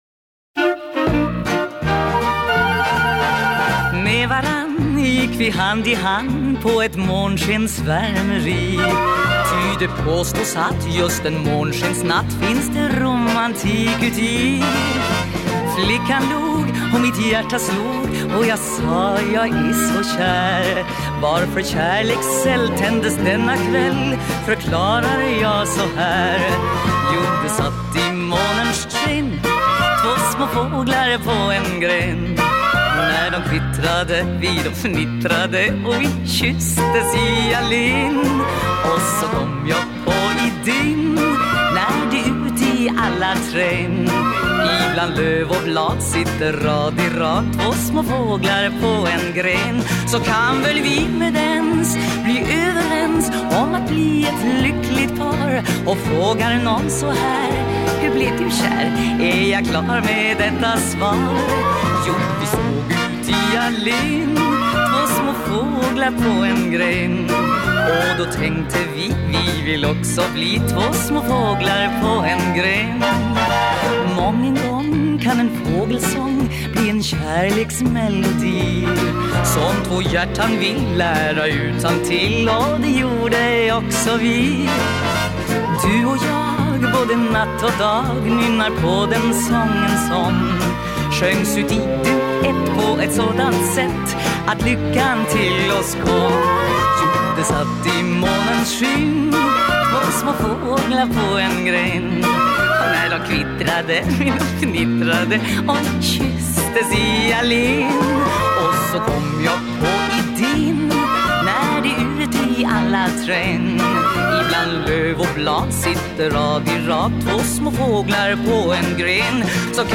Jag tar en till av Norrlands schlagerlåtar!